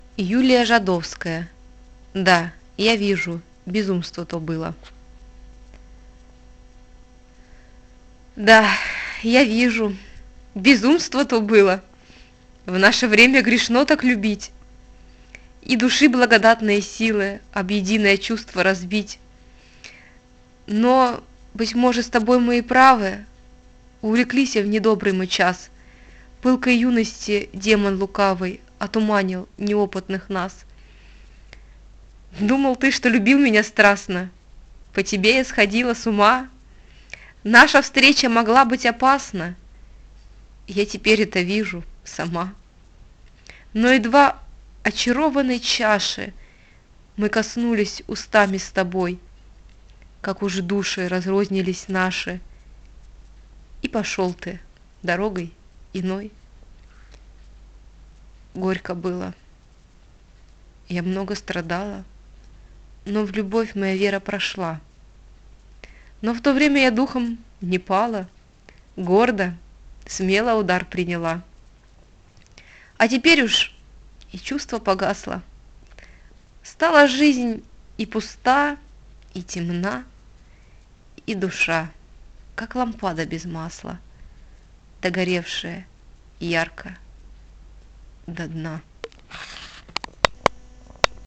yu-zhadovskaya-da-ya-vizhu-bezumstvo-to-bylo-chit-a-makarevich